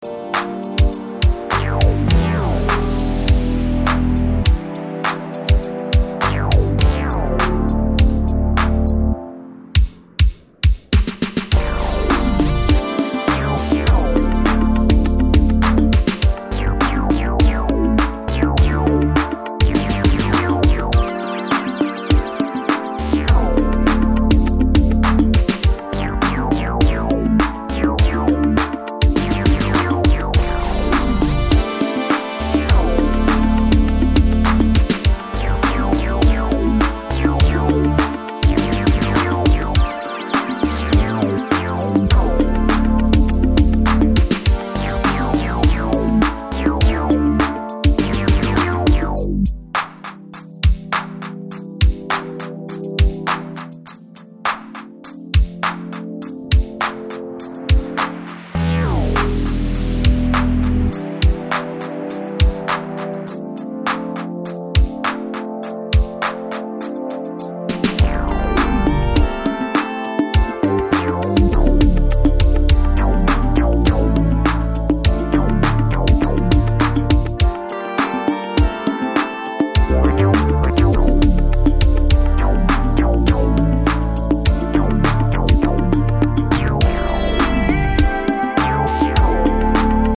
Exiting boogie tracks with a overdose on funk and sexyness!